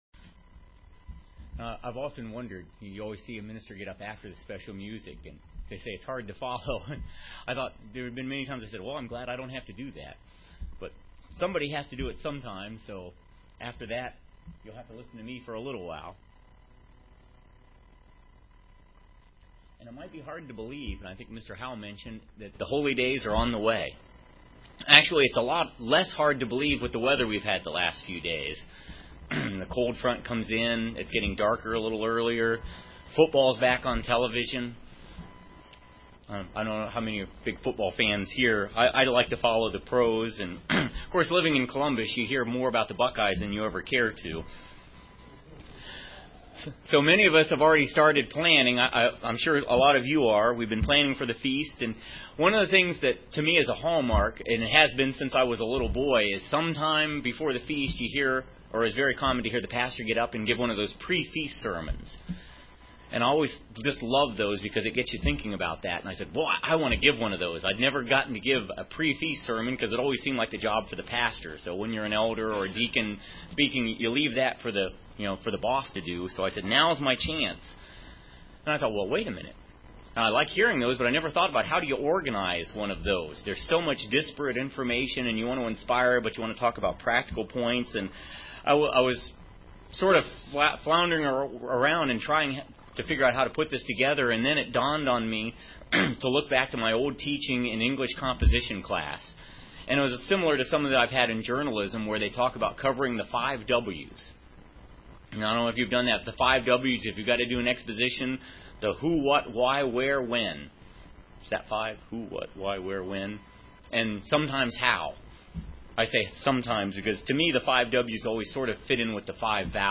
Many of us have already begun planning for the Feast. We always hear pre-Feast sermons.